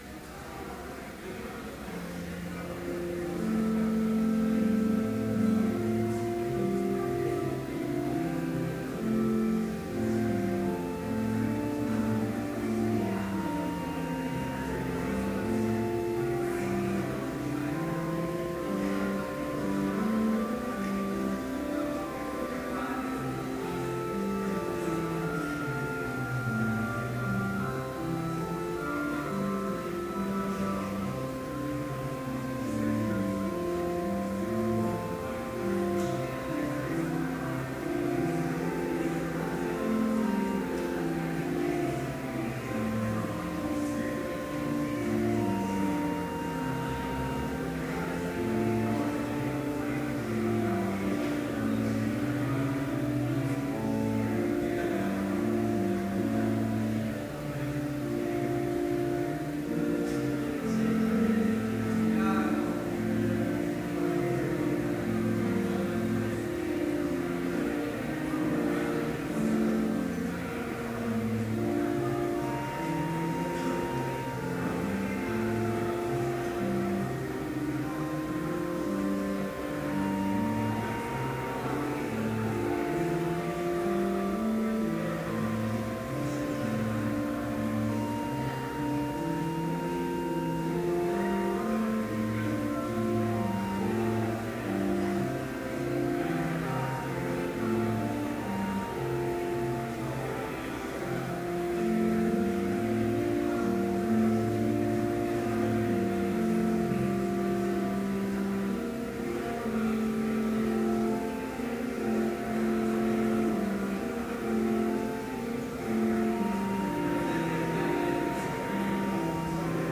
Complete service audio for Chapel - February 27, 2013